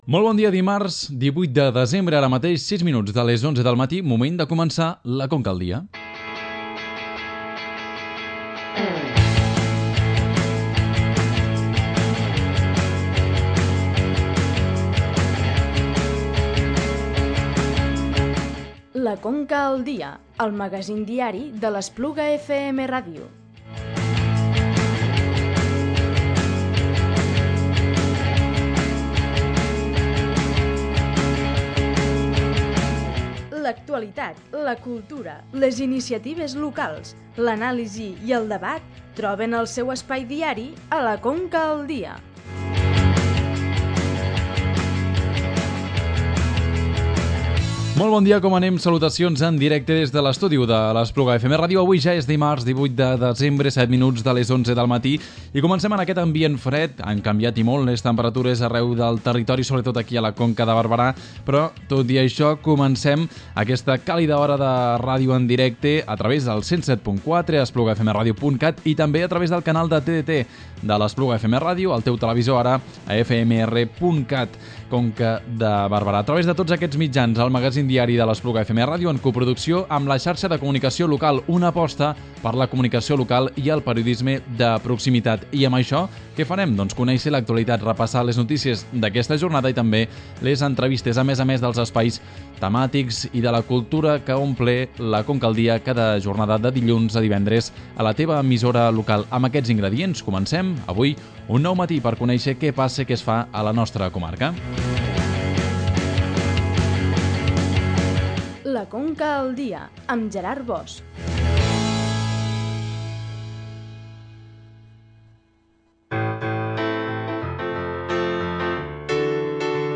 Aquest dimarts hem parlat del pressupost de l’Ajuntament de Vimbodí i Poblet per al 2019, ens ha explicat els números l’alcalde del municipi, Joan Güell. Hem seguit explicant que la Ruta del Cister reeditarà el talonari de propostes culturals per al 2019 i que El Francolí ha rebut un reconeixement dels Premis Ateneus 2018. A més a més, parlem del CTT Espluga i d’algunes propostes culturals que podem fer aquests dies a la comarca.